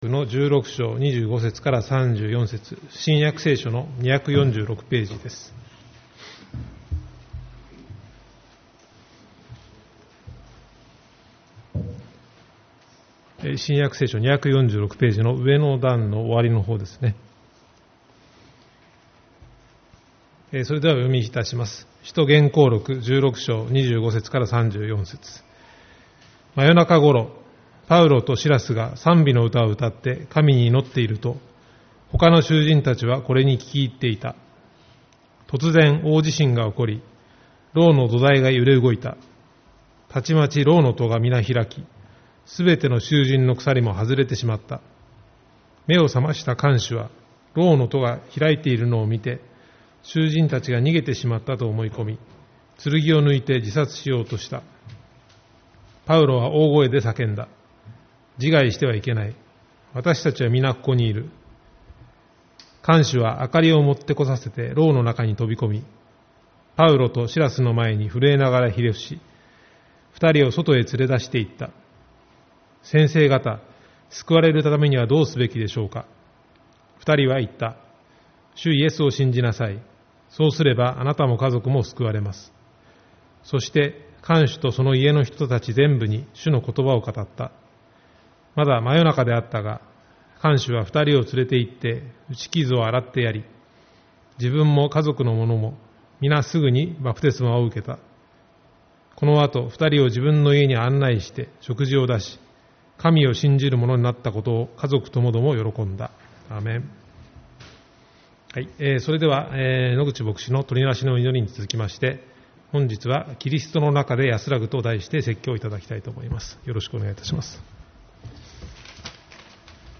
7月7日主日礼拝 「キリストの中で安らぐ」